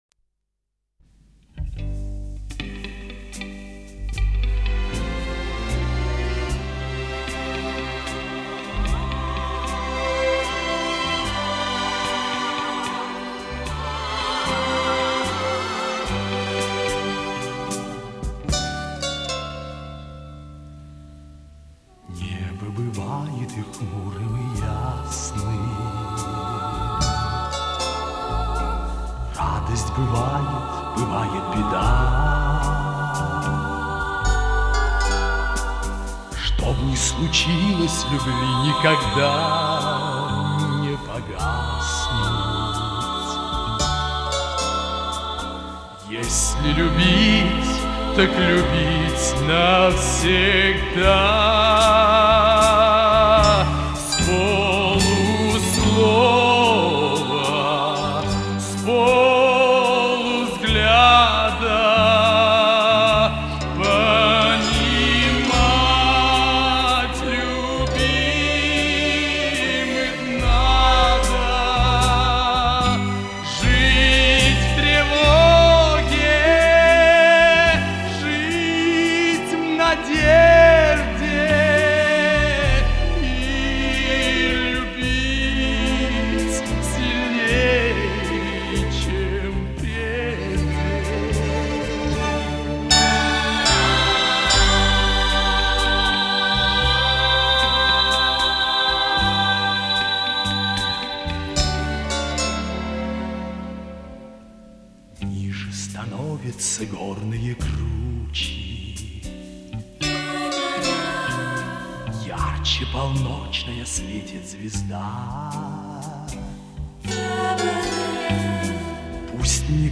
Мы в этой песне бэк-вокал писали.